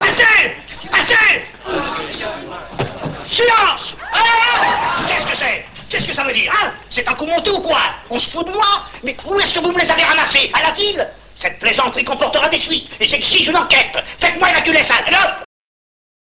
Extraits sonores du film